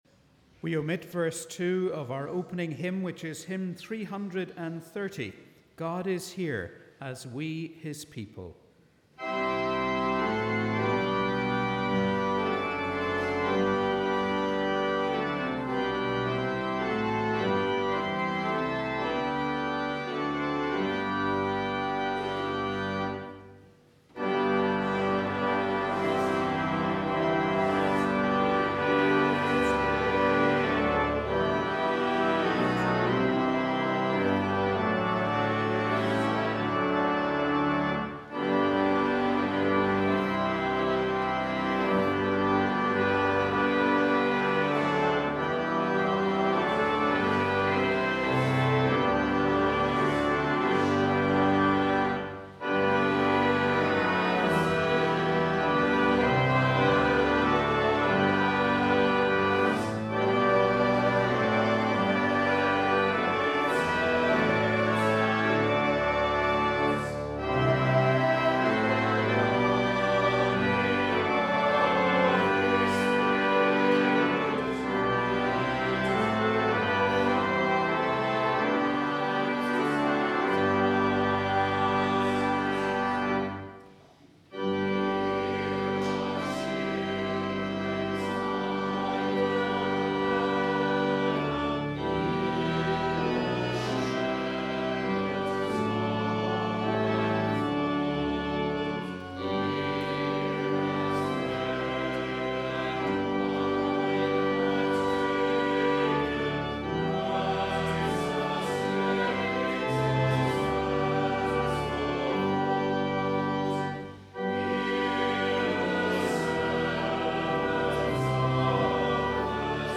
We warmly welcome you to our service of Morning Prayer on the 3rd Sunday after the Epiphany.